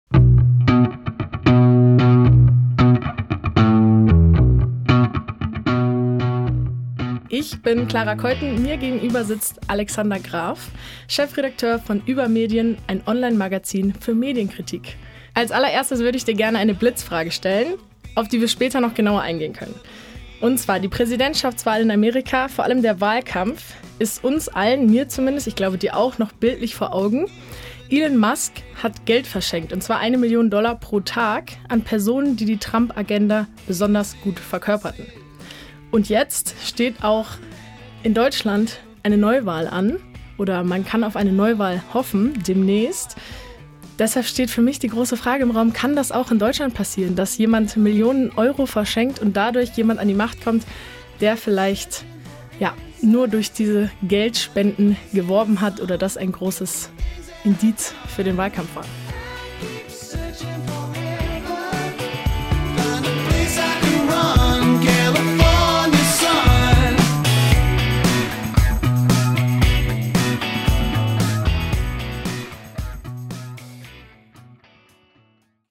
Studiogespräch